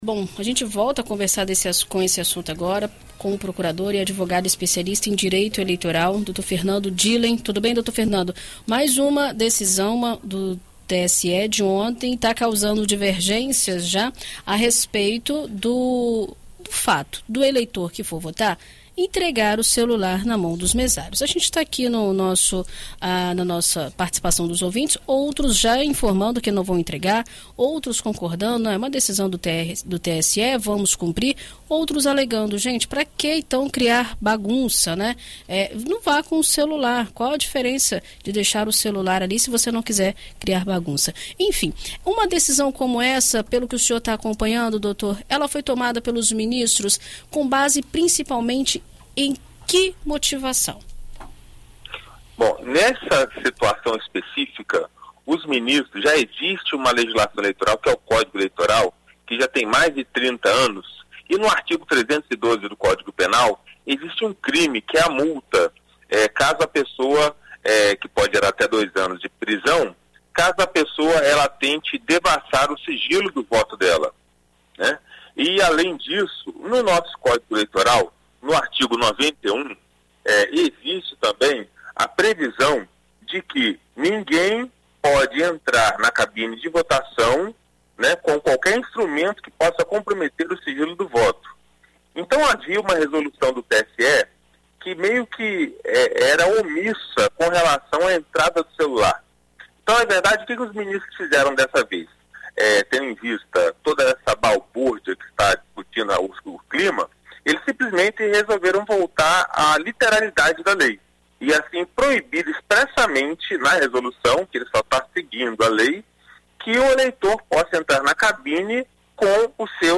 Em entrevista à BandNews FM Espírito Santo nesta sexta-feira (26)